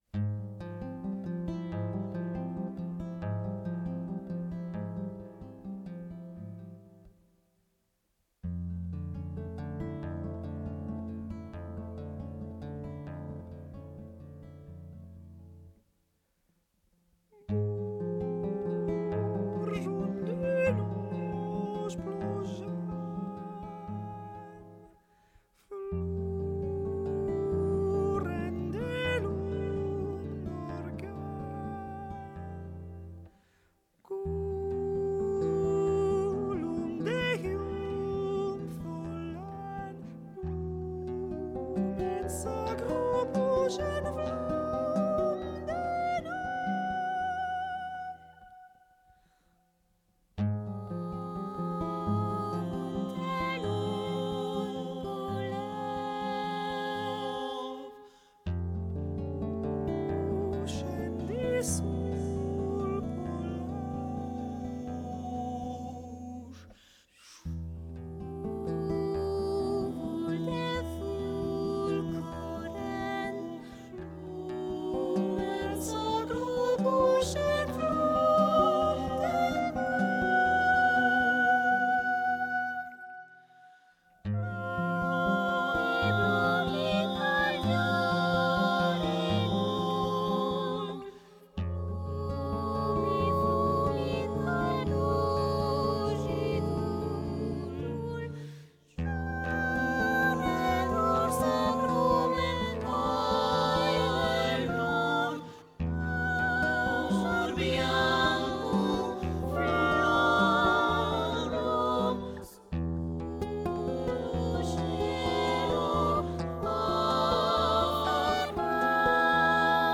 30 voix de femmes et une guitare
Guitare